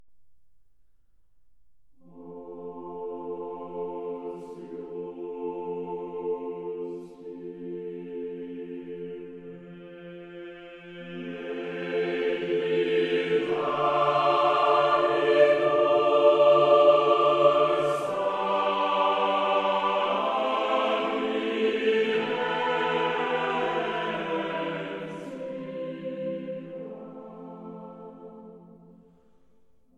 10. Bruckner- Os Justi Meditabitur tónica fa
Lidio_Bruckner-_Os_Justi_Meditabitur-_Monteverdi_Choir.mp3